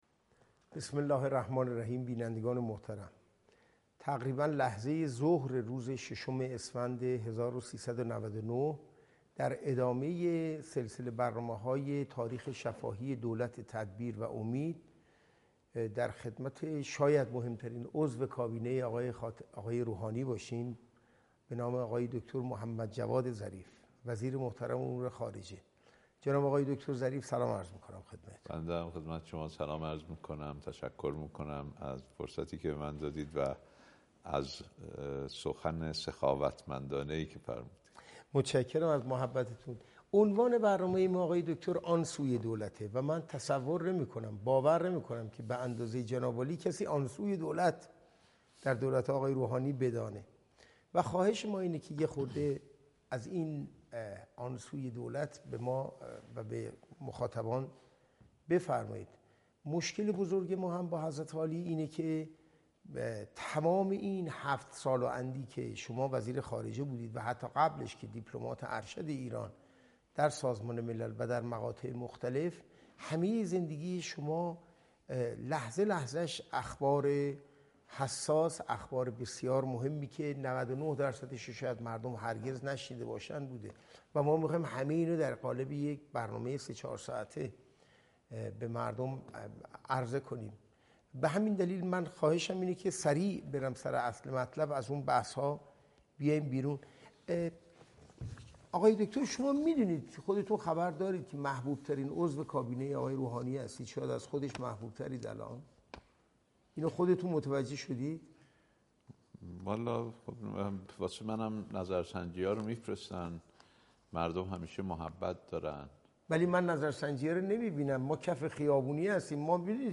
مصاحبه سه ساعته محمدجواد ظریف